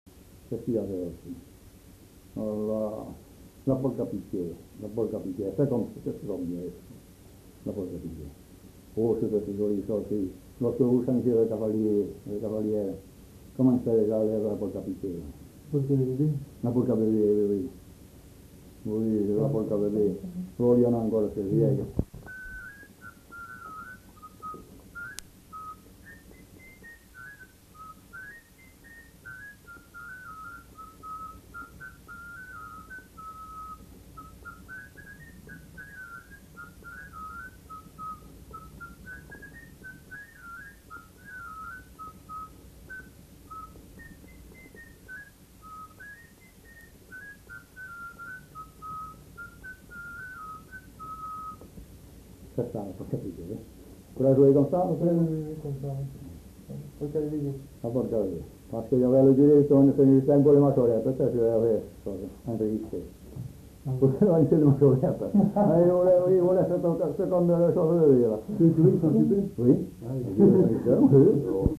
Répertoire de danses du Gabardan joué à la flûte de Pan et à l'harmonica